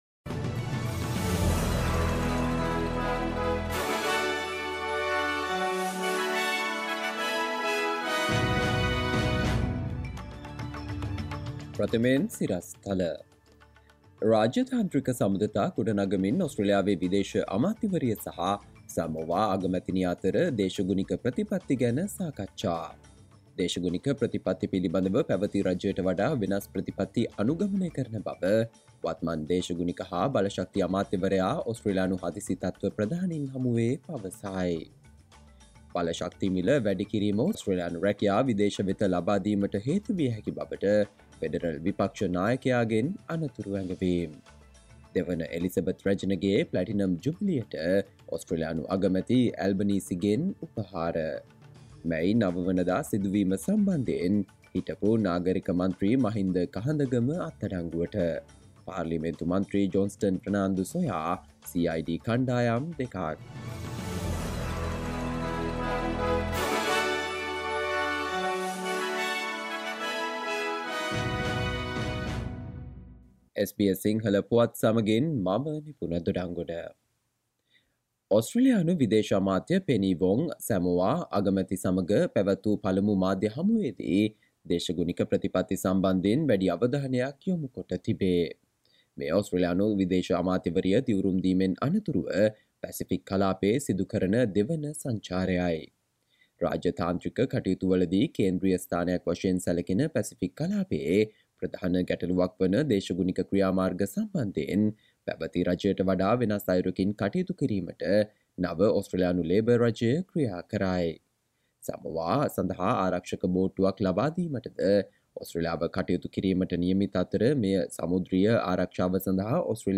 SBS Sinhala Radio News on 03 June 2022: Increasing energy prices could lead to Australian jobs being sent offshore, Opposition Leader warns
Listen to the latest news from Australia, Sri Lanka, across the globe, and the latest news from the sports world on SBS Sinhala radio news bulletin – Friday 03 June 2022